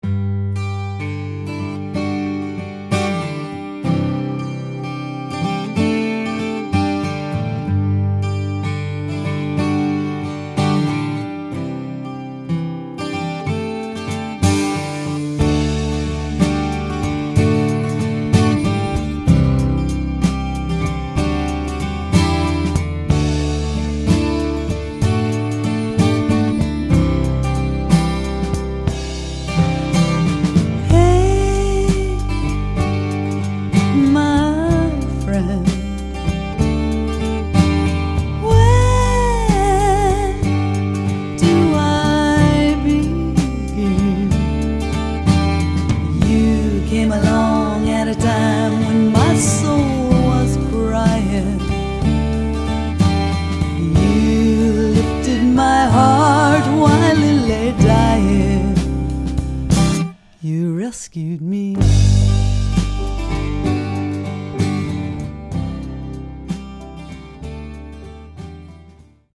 An alternative blend of Funk, Jazz, Rock, Pop, and Blues.
driven by the powerful vocals
rock solid bass lines
the sensitive, melodic guitar
The more upbeat
bass